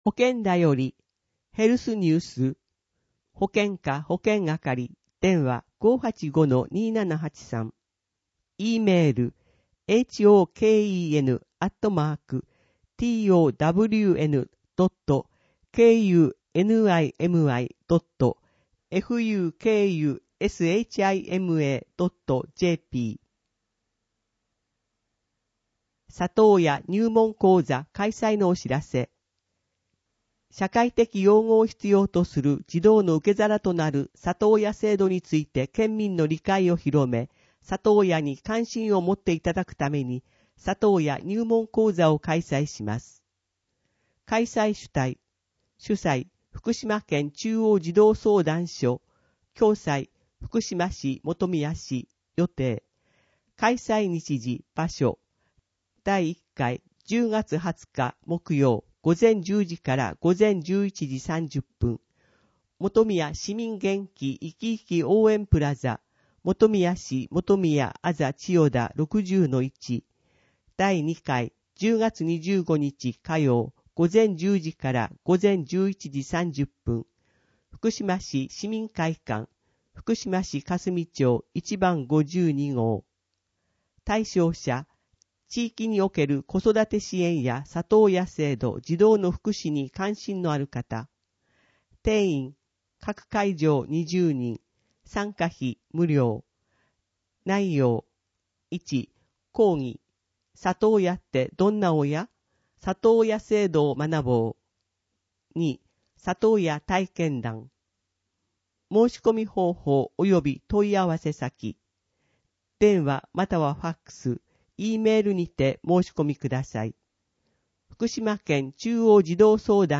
＜外部リンク＞ 声の広報 声の広報（1） [その他のファイル／9.05MB] 声の広報（2） [その他のファイル／10.01MB]